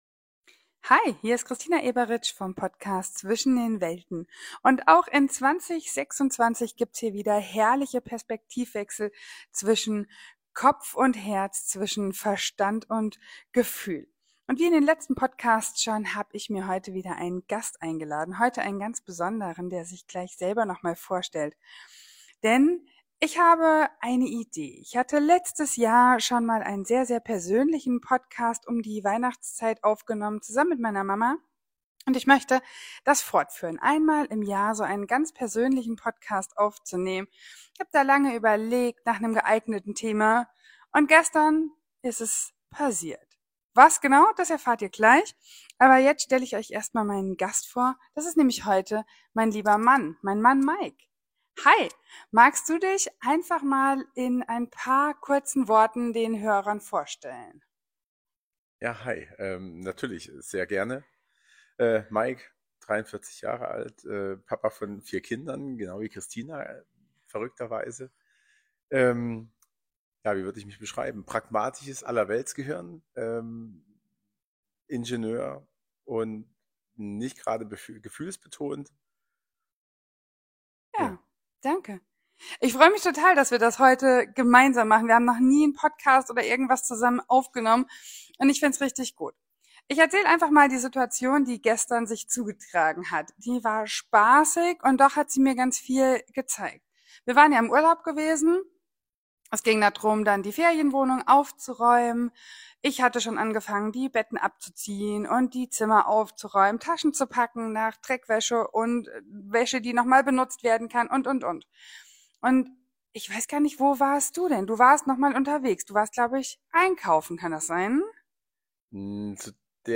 Heute gibt es einen ganz persönlichen Podcast mit meinem Mann. Wir sprechen darüber, wie wir es als Paar und Eltern geschafft haben, nicht mehr über den Wert von Arbeiten zu diskutieren.